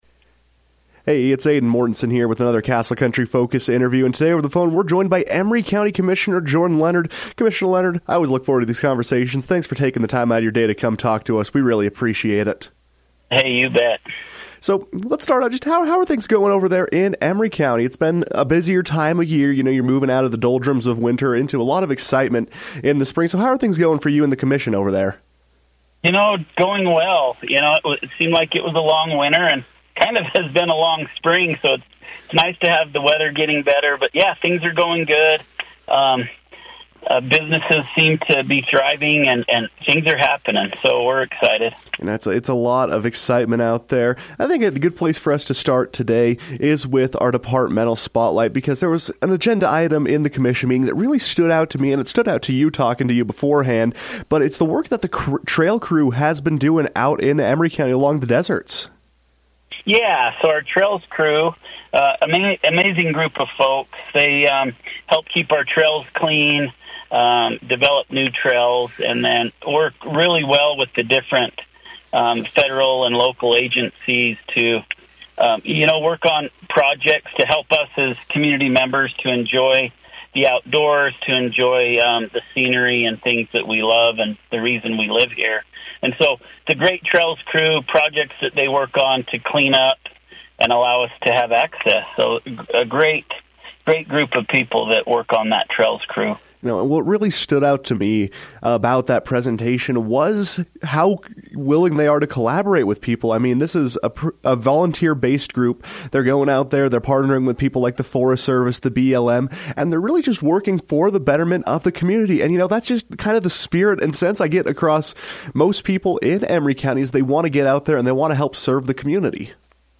To speak on what's happening around Emery, Commissioner Jordan Leonard joined the KOAL Newsroom to give insight on the inner workings of the county.